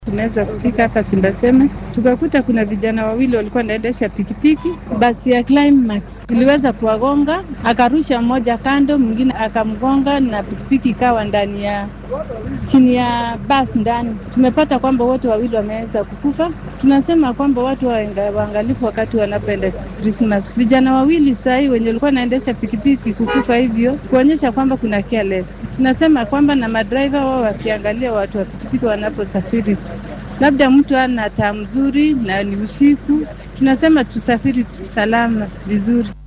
Goobjooge-Shilka-Nakuru.mp3